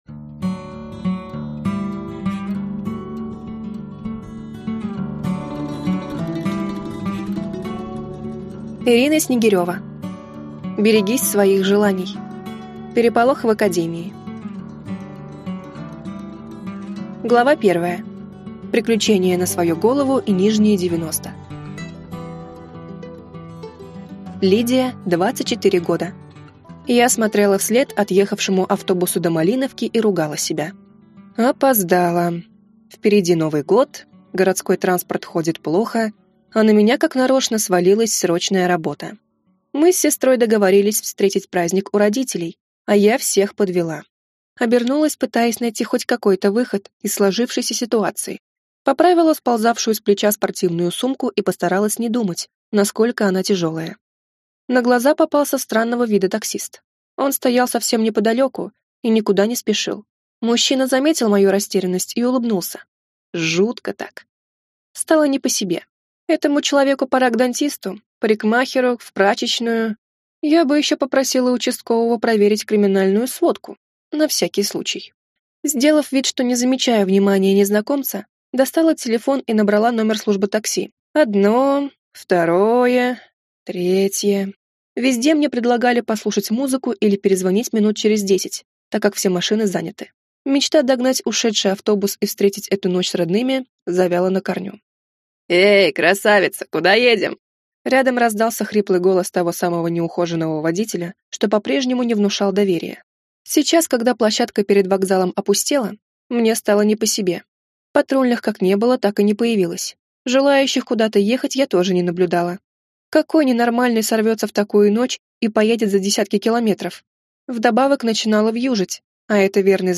Аудиокнига Берегись своих желаний. Переполох в академии | Библиотека аудиокниг